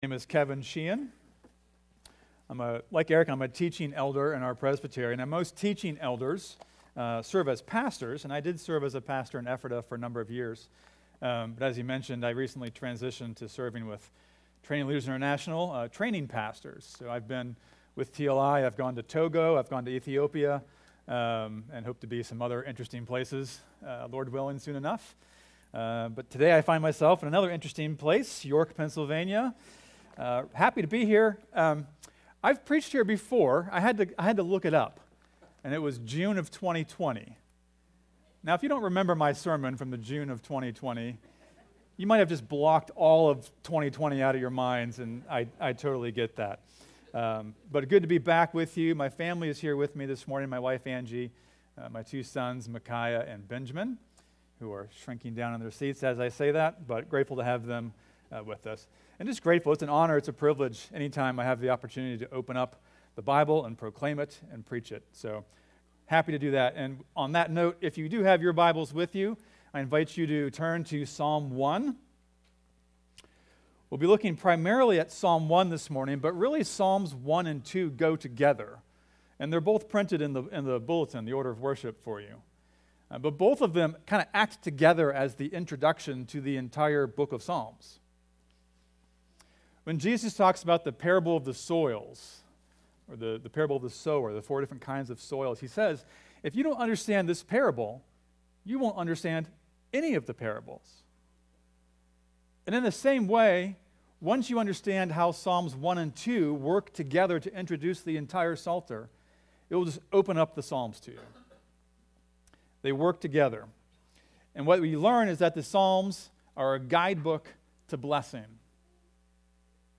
3.23.25-sermon-audio.mp3